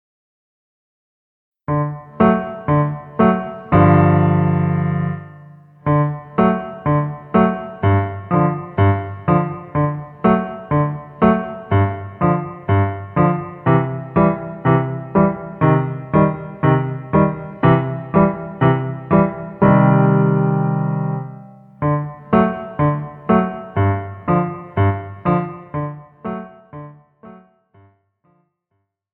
Besetzung: Instrumentalnoten für Viola